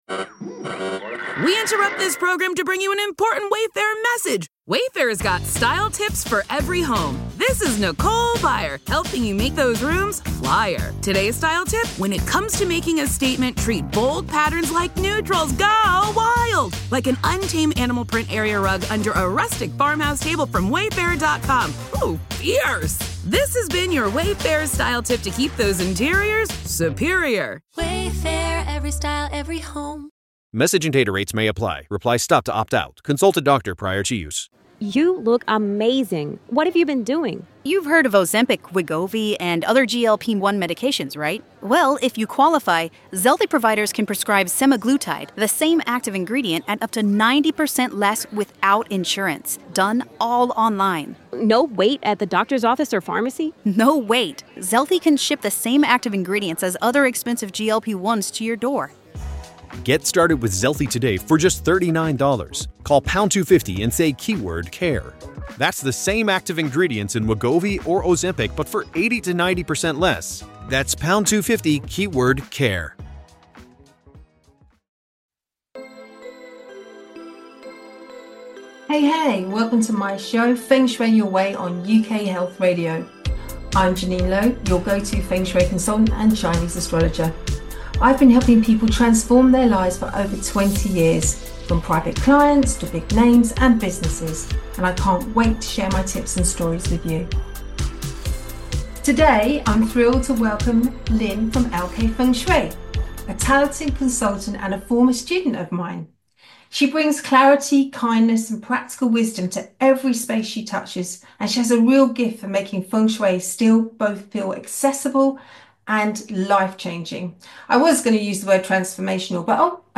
But that’s not all Feng Shui Your Way is also a platform for meaningful conversations.